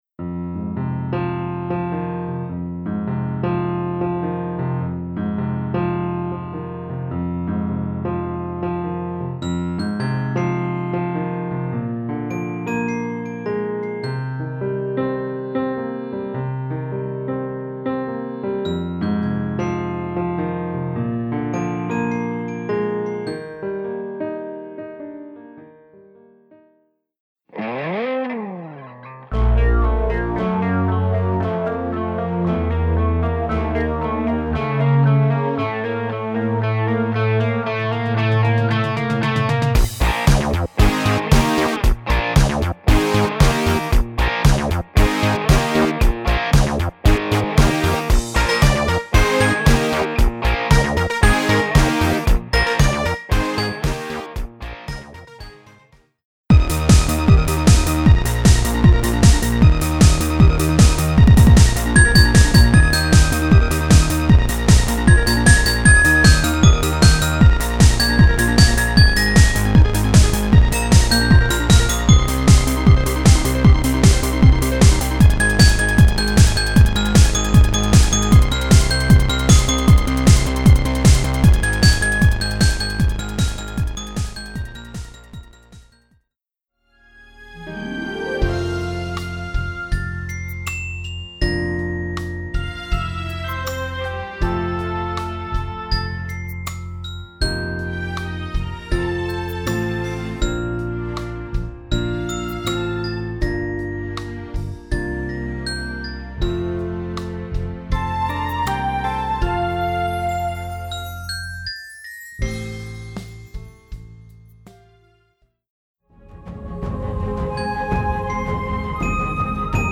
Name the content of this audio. Voicing: Mallets